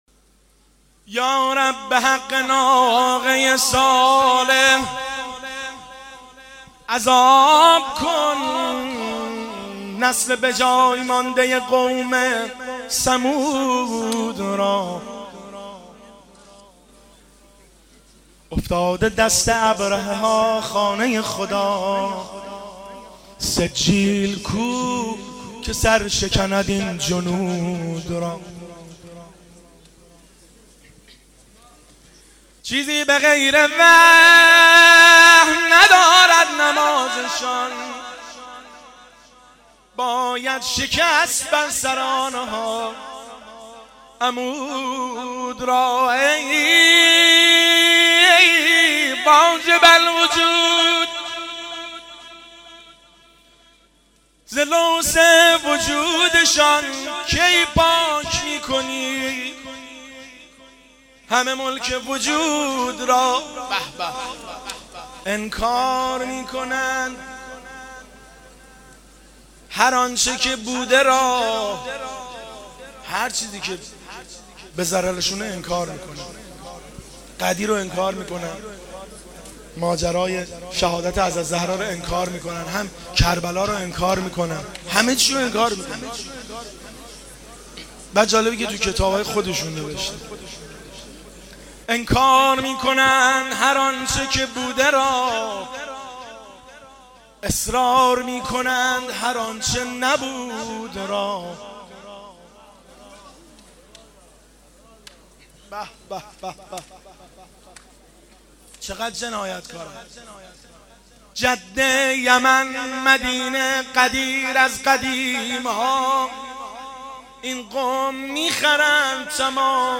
روضه پایانی